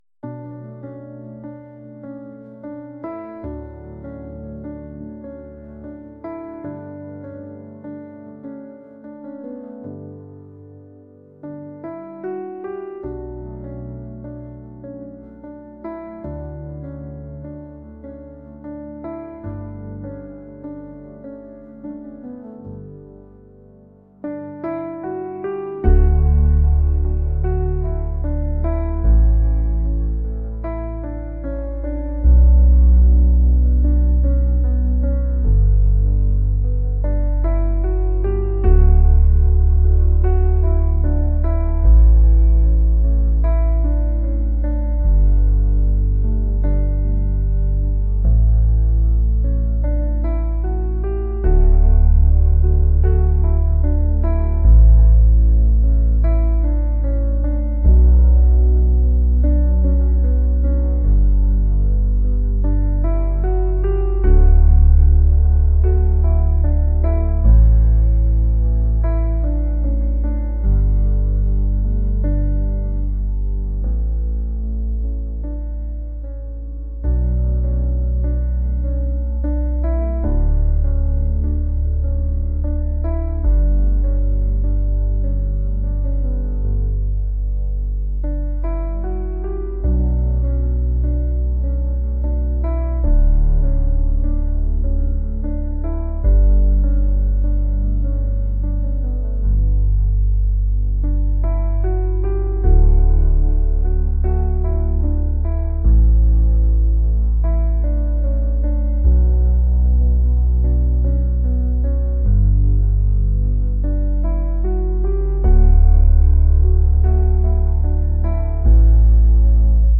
acoustic | pop | soul & rnb